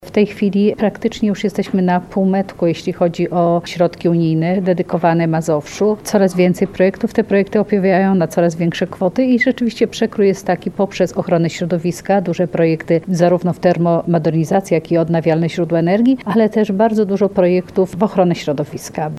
– Mazowsze prężnie wykorzystuje dodatkowe fundusze – ocenia wicemarszałek województwa Elżbieta Lanc.